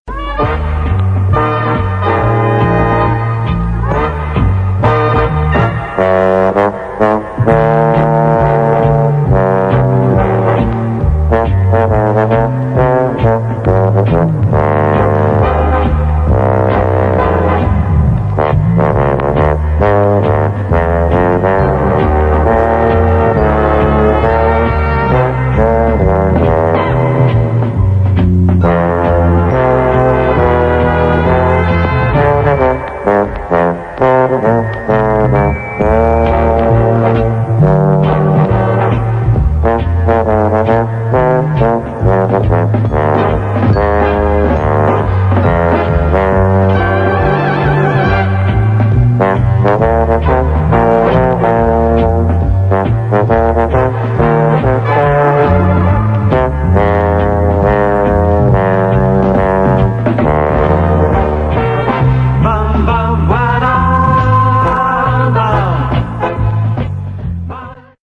Прошу помочь определить инструментальную пьесу.